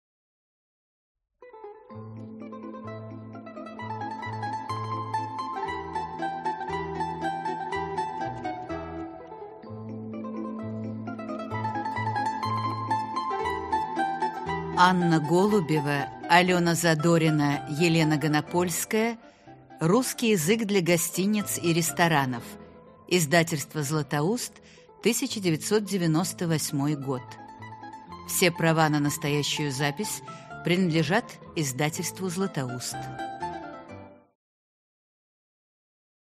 Аудиокнига Русский язык для гостиниц и ресторанов (начальный курс) | Библиотека аудиокниг